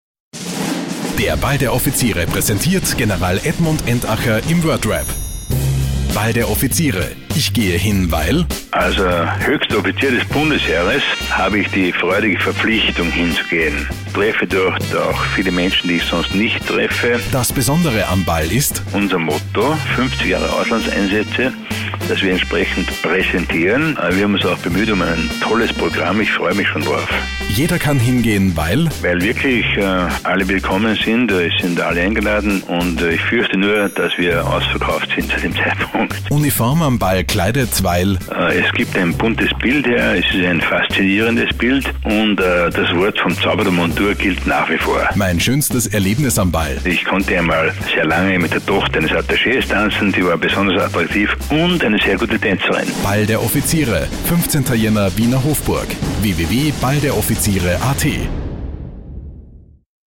Wordrap: Generalstabschef Entacher über den Ball Warum Lynne von den Rounder Girls den Ball ebenfalls besucht Auch Eric Papilaya kommt in die Hofburg Video-Trailer zum Ball Website des Balls der Offiziere